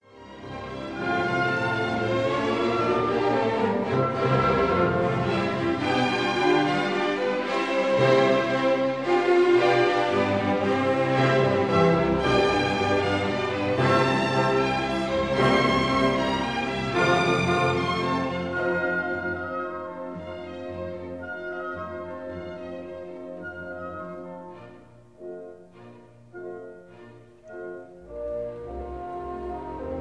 stereo recording